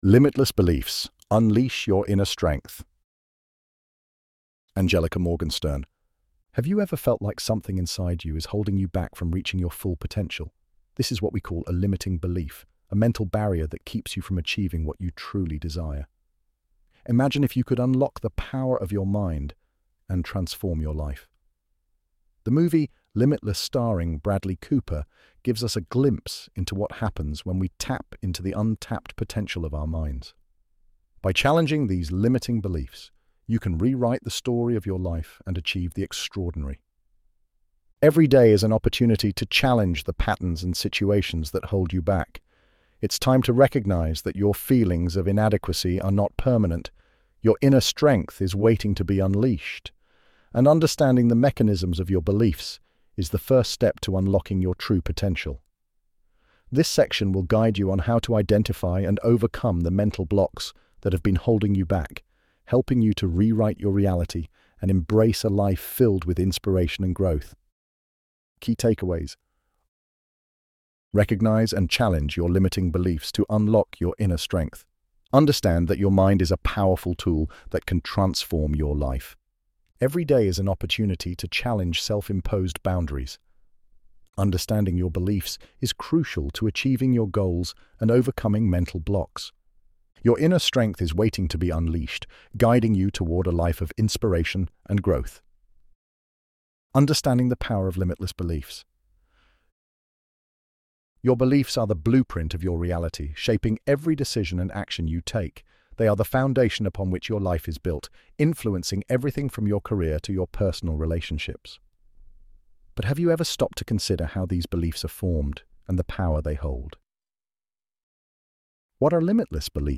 ElevenLabs_Limitless_Beliefs_Unleash_Your_Inner_Strength.mp3